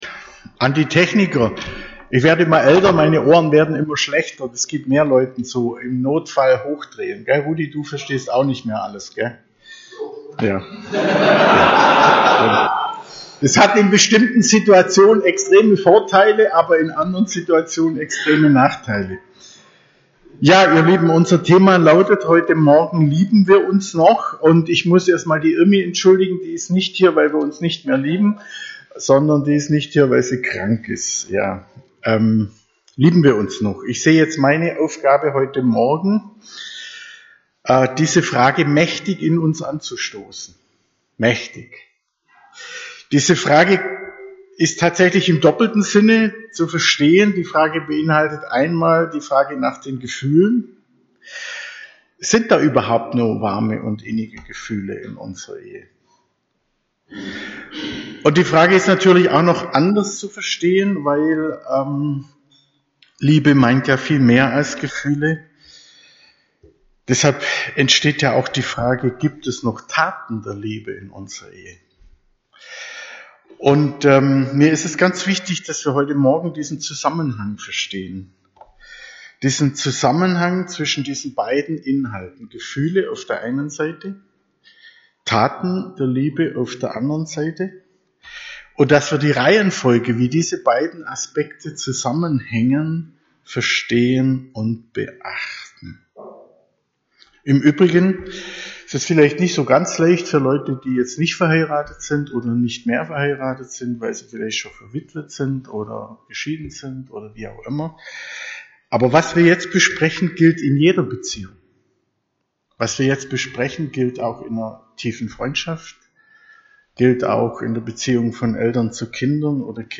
EFG Erding – Predigten